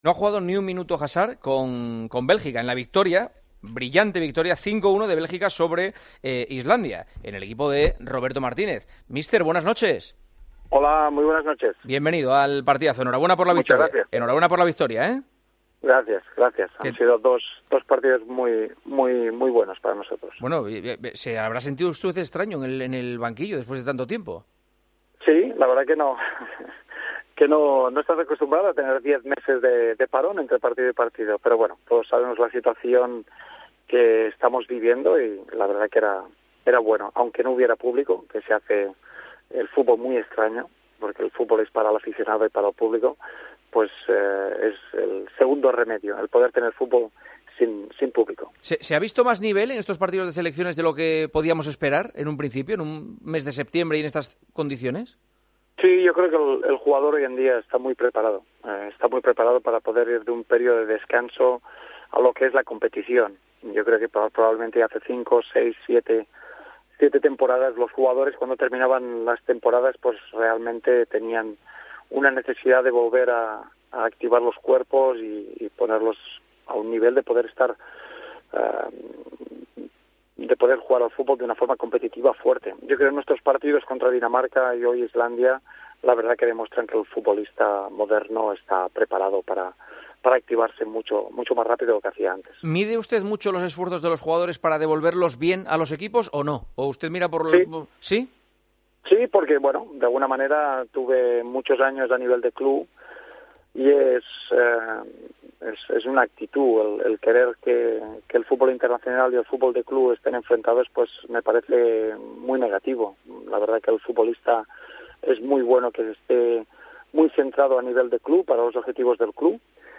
AUDIO - ENTREVISTA A ROBERTO MARTÍNEZ, SELECCIONADOR DE BÉLGICA, EN EL PARTIDAZO DE COPE